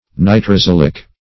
Search Result for " nitrosylic" : The Collaborative International Dictionary of English v.0.48: Nitrosylic \Ni`tro*syl"ic\, a. (Chem.)
nitrosylic.mp3